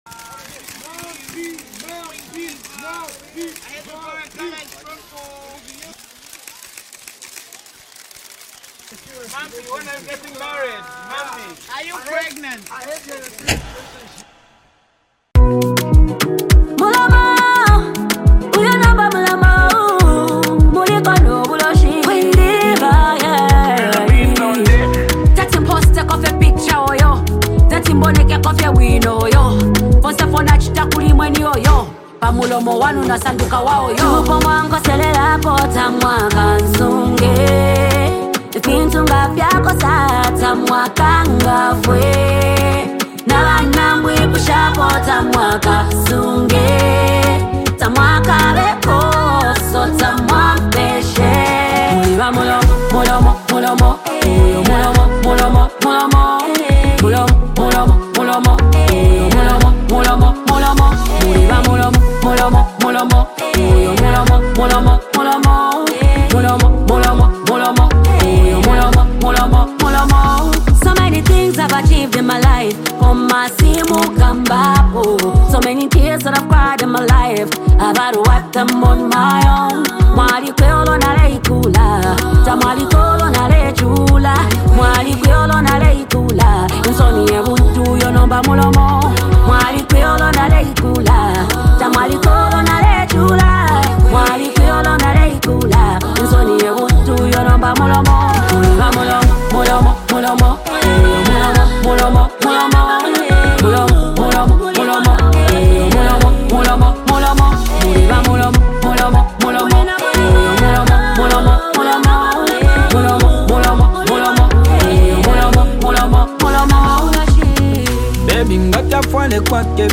is a powerful, emotionally rich song
soulful and emotional delivery